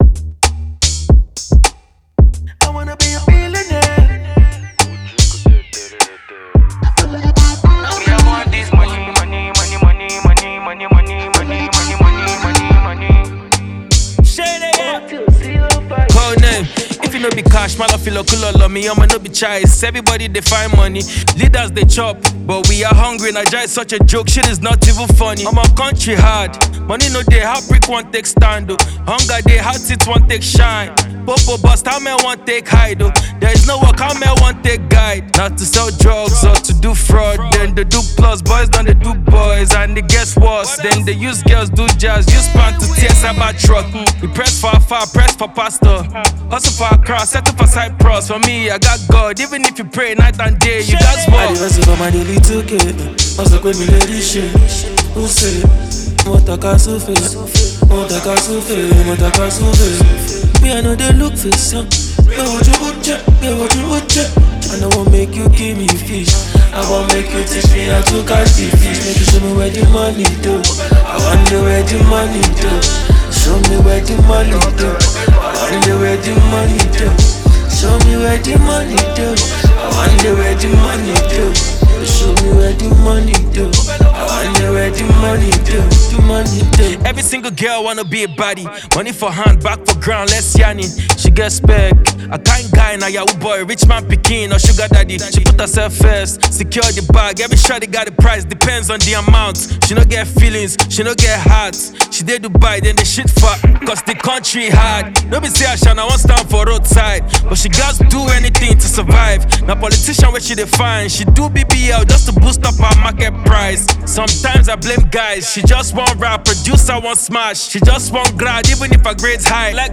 Nigerian rapper